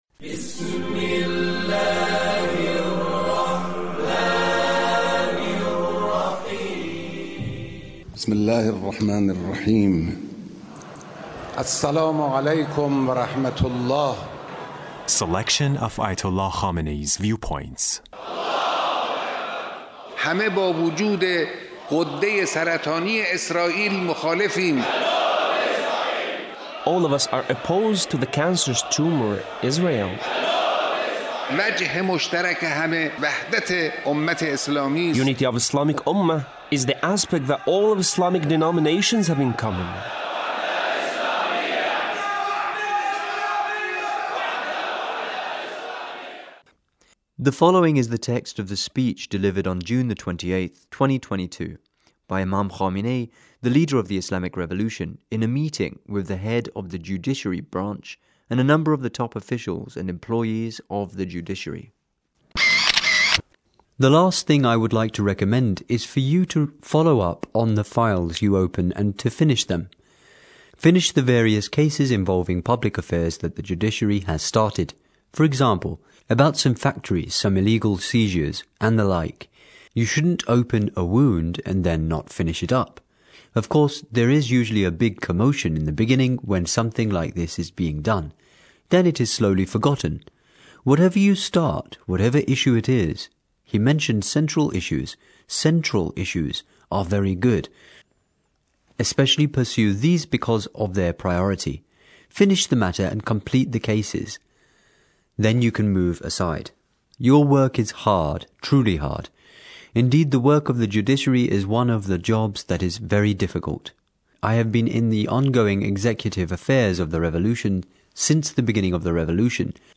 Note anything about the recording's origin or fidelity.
The Leader's speech in a meeting with a number of the top officials and employees of the Judiciary.